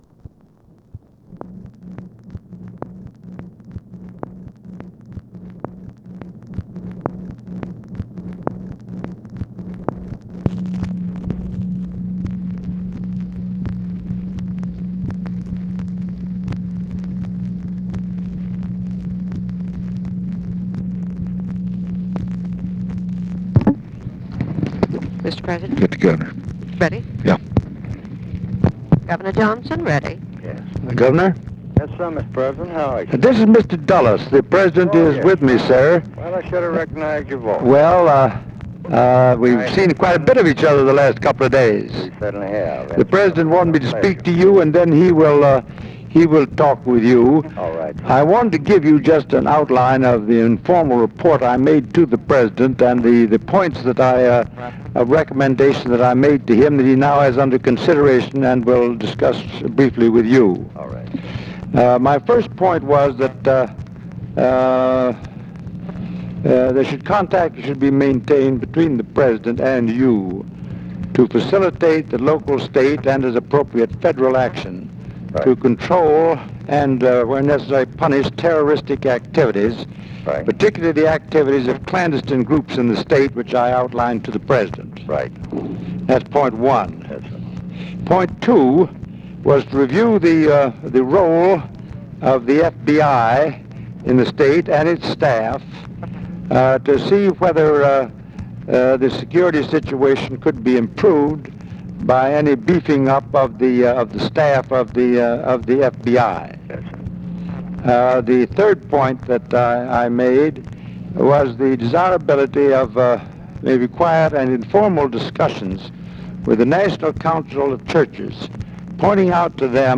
Conversation with PAUL JOHNSON and ALLEN DULLES, June 26, 1964
Secret White House Tapes